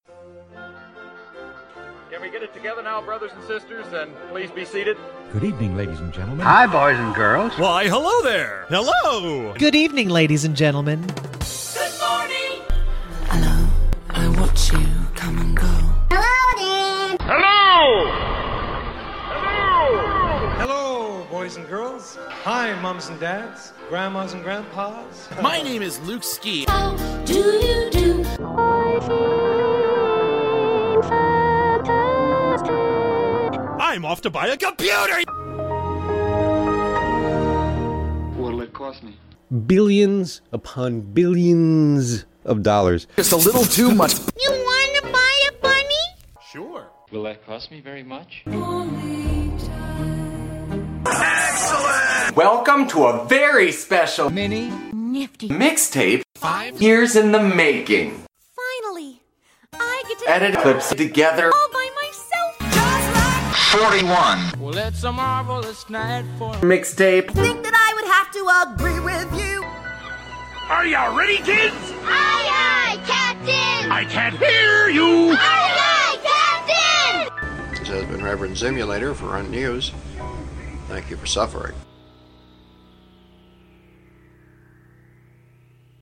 Keep in mind that this is not a rap/hip-hop mixtape; this is a collage of clips from different movies, songs, shows, etc. edited together, usually humorously or artistically. This first track, "Welcome to the Tape", is mostly people saying hello and welcome, with other things added for comic effect.
This is entirely made of samples; I didn't create any of these samples, I just mixed them!